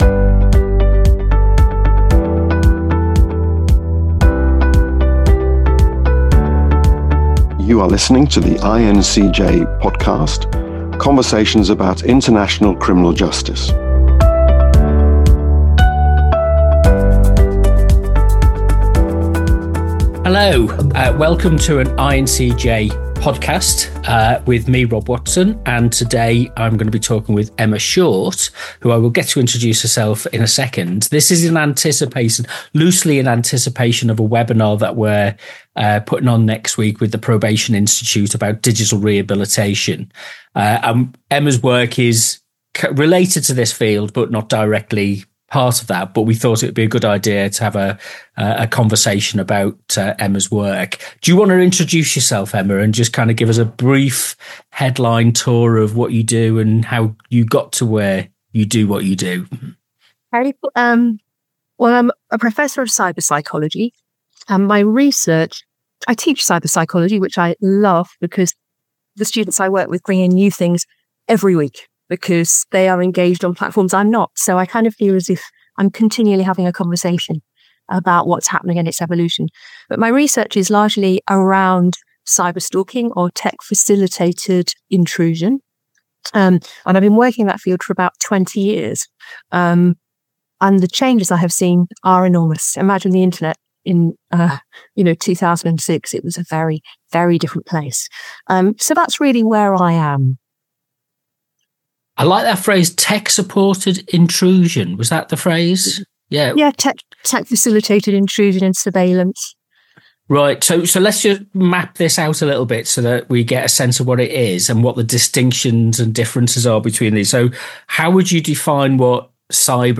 The conversation sits at the intersection of psychology, technology, and criminal justice, raising questions that current legal and institutional frameworks are still struggling to answer.